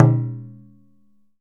DJUN DJUN06L.wav